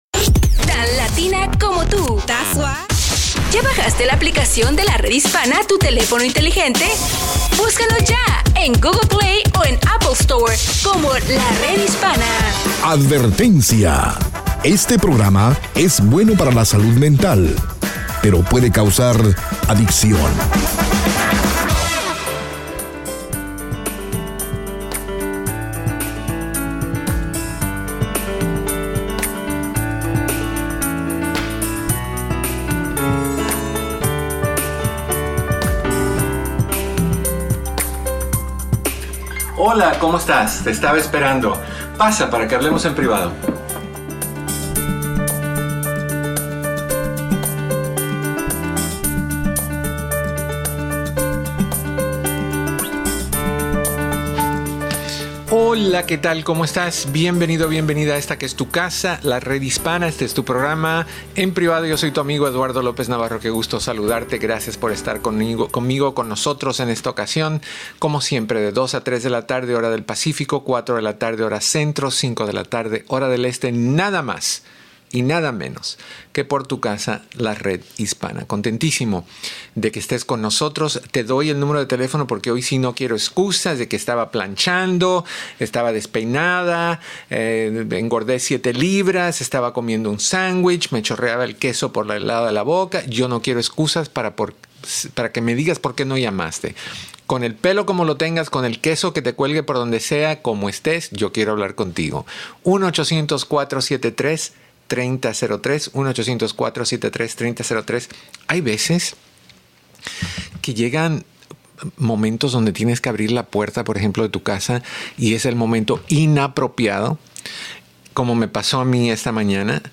Escucha el programa de radio EN PRIVADO, de Lunes a Viernes a las 2 P.M. hora del Pacífico, 4 P.M. hora Central y 5 P.M. hora del Este por La Red Hispana y todas sus afiliadas.